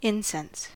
incense-us.mp3